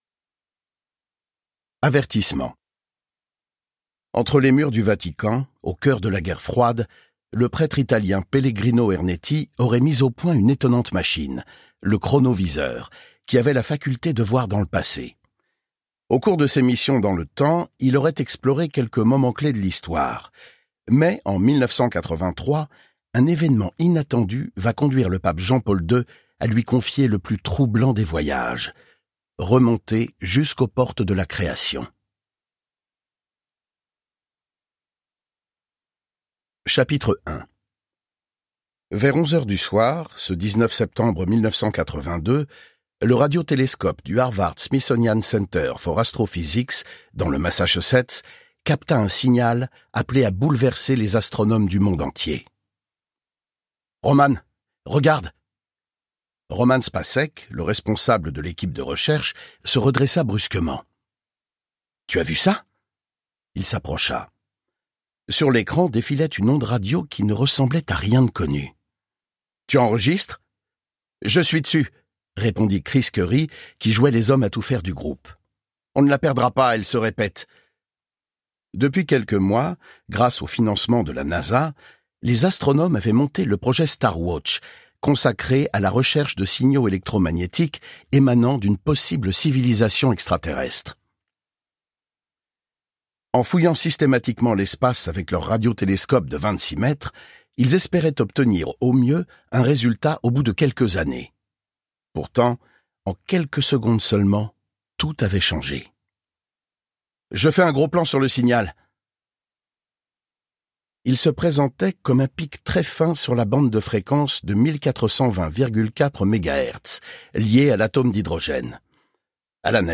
Click for an excerpt - Ernetti et le voyage interdit de Roland Portiche